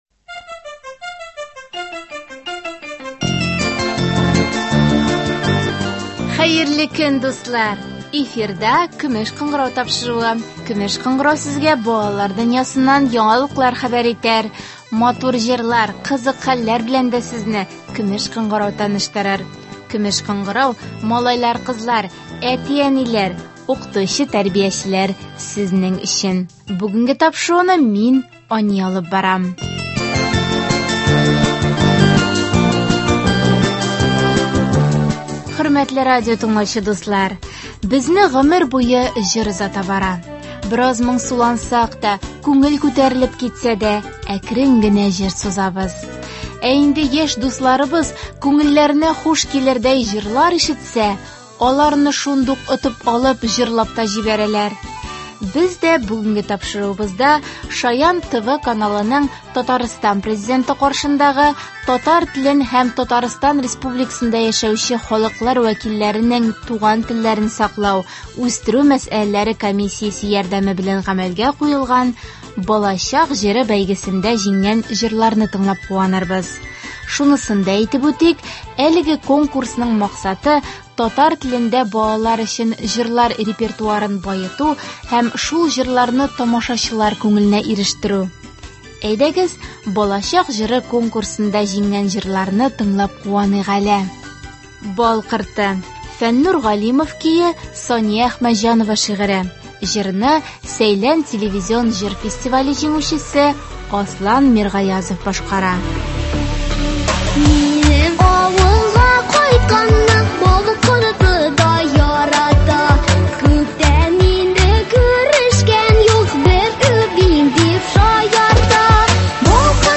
җиңгән җырларны тыңларбыз